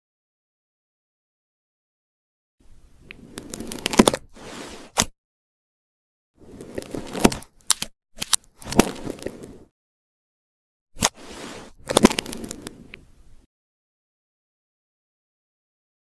Tiny bubble slices. Oddly satisfying sound effects free download